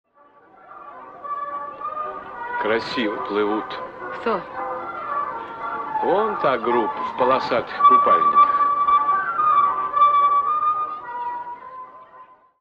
Звук с фразой из кинофильма Полосатый рейс красиво плывут вон та группа в полосатых купальниках